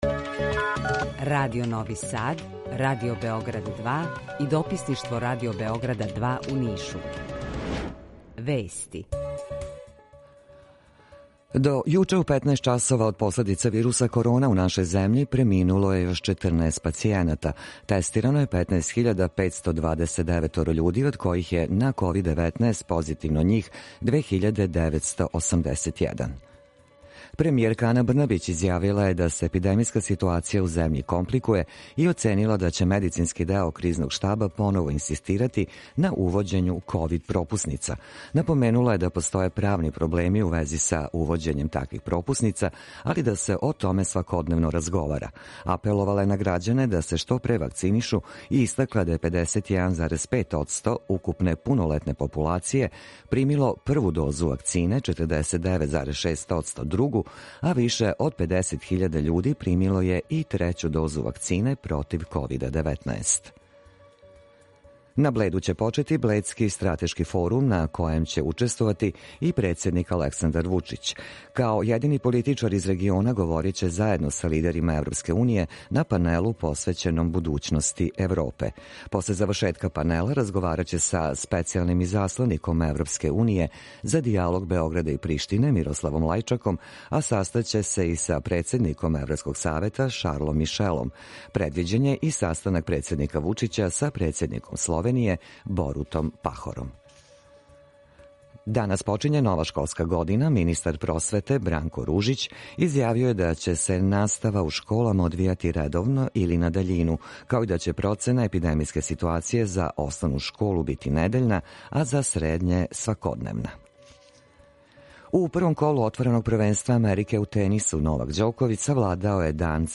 Јутарњи програм из три студија
Укључење Косовске Митровице
У два сата, ту је и добра музика, другачија у односу на остале радио-станице.